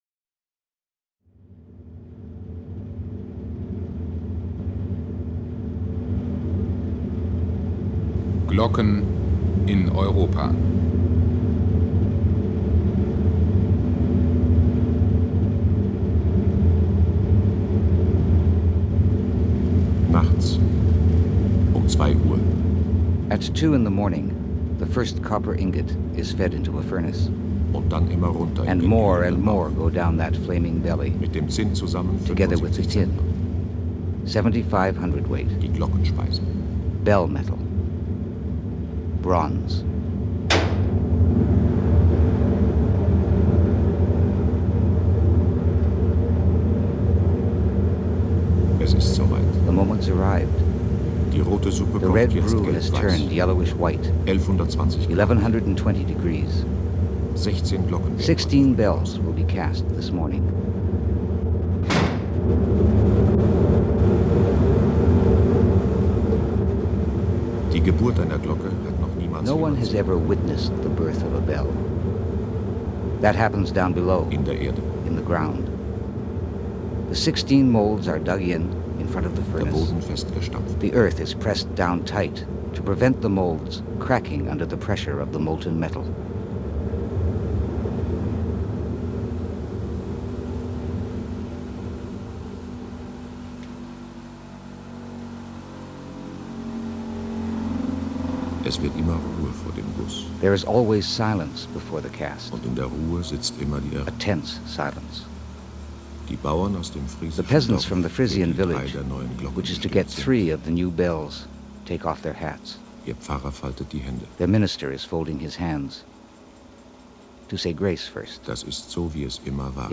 This is a great radio doc which was originally done in German.
This is a translation version which is OK.
03_Bells_IN_Europe.mp3